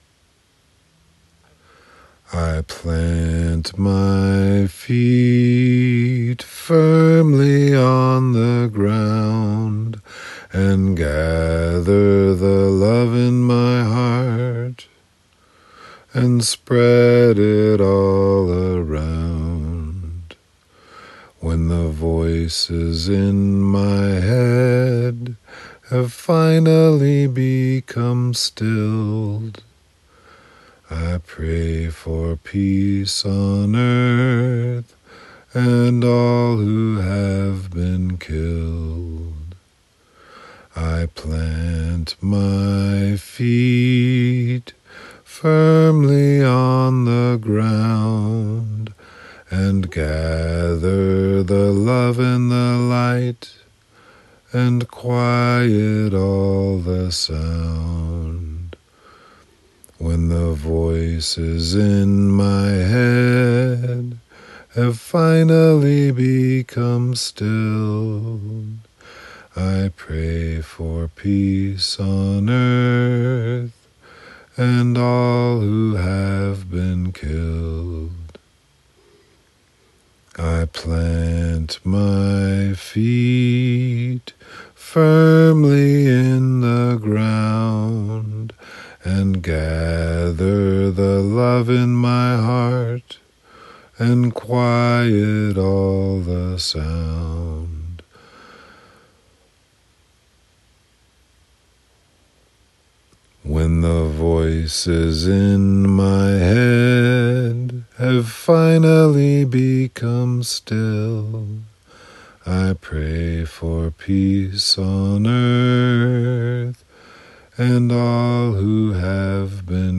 I went with something to wish for and I got a couple of body parts in there did a cappella for a change.